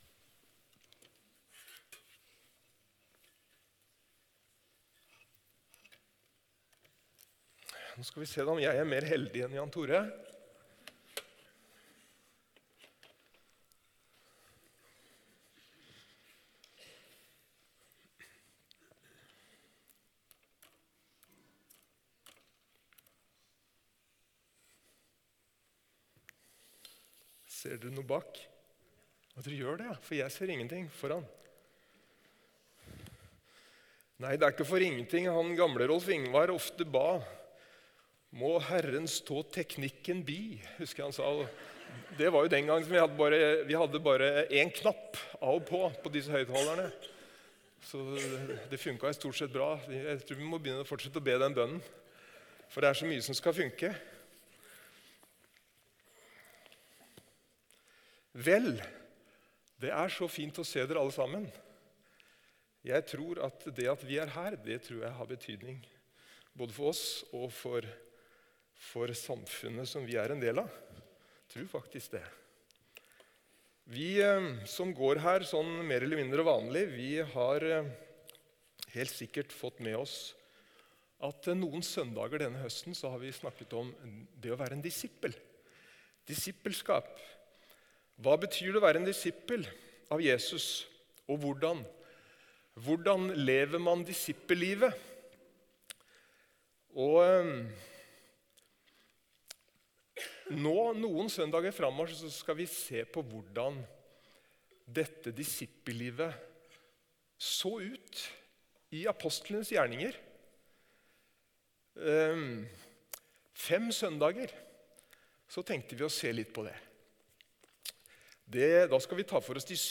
Tale